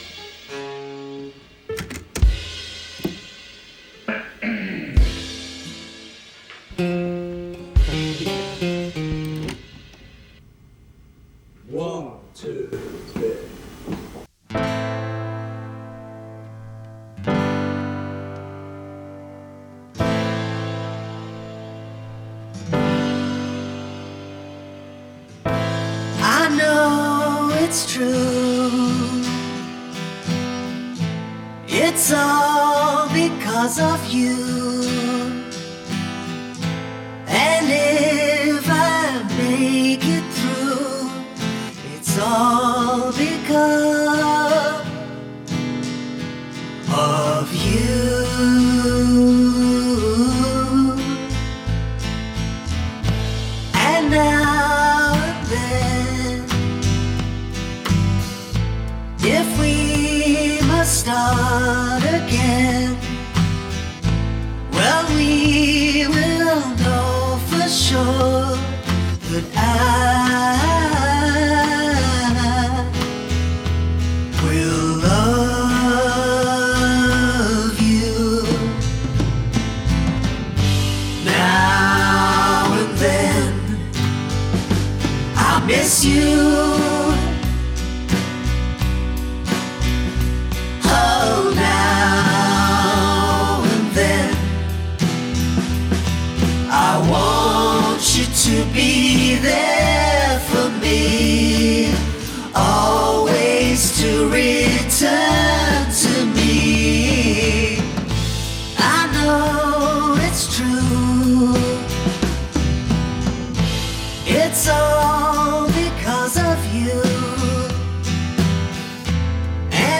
slide guitar
guitars, bass and piano
I wanted the song to be close to the original.